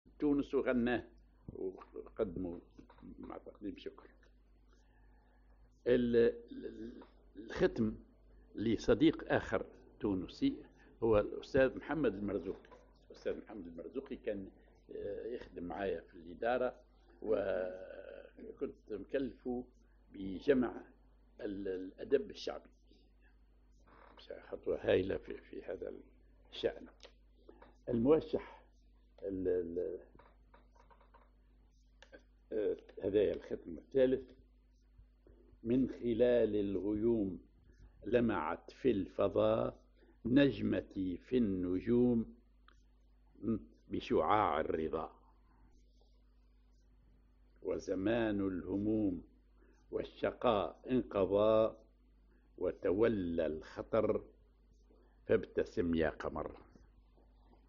Maqam ar جهاركاه
Rhythm ar سماعي طائر
genre نشيد